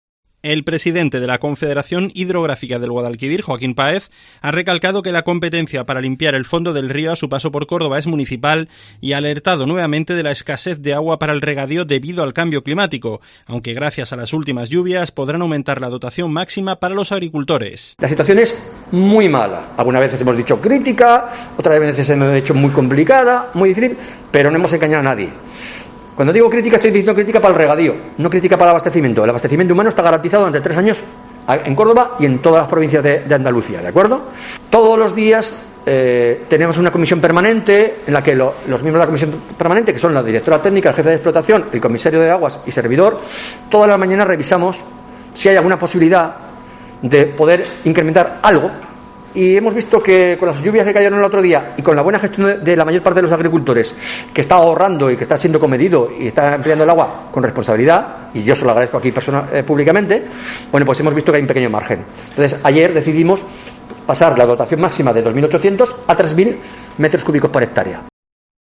Su presidente Joaquín Páez explica que gracias a las últimas lluvias la dotación para el Sistema de Regulación General ha subido en 200 metros cúbicos por hectárea